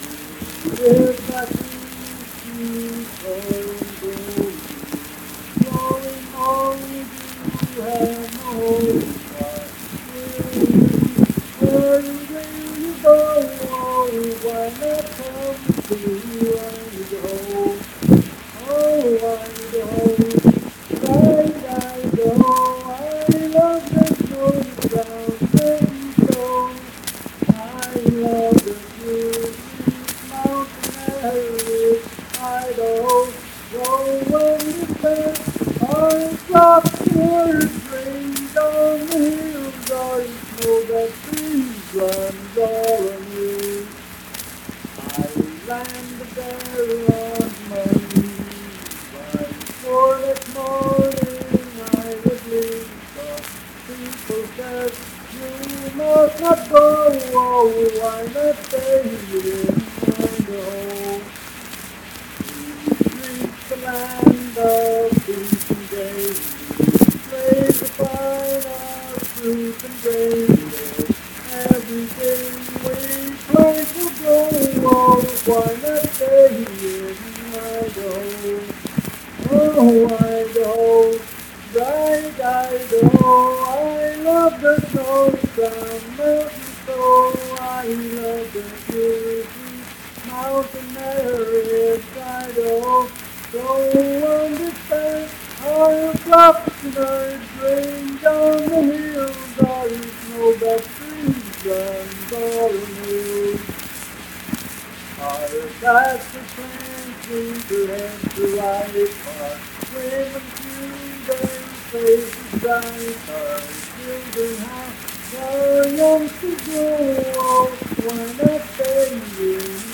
Unaccompanied vocal music performance
Verse-refrain 3d(8) & Rd(8).
Miscellaneous--Musical
Voice (sung)